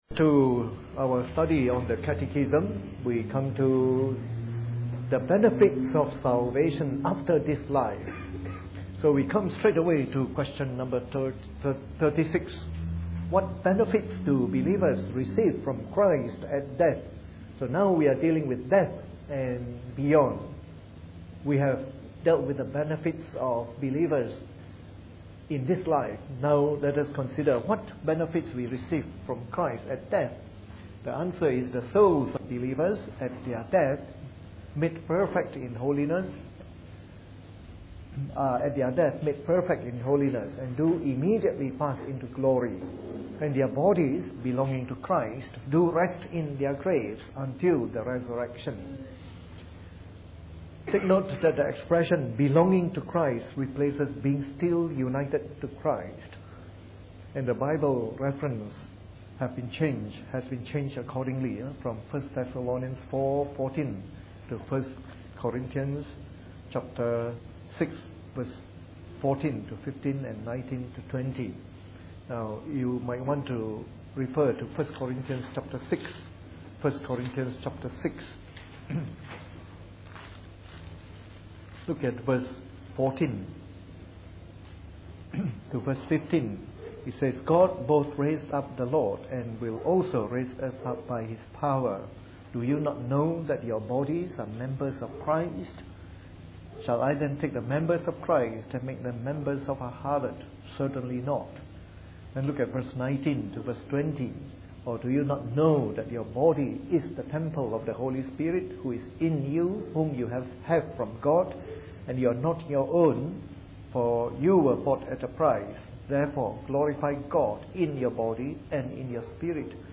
Preached on the 6th of April 2011 during the Bible Study from our current series on the Shorter Catechism.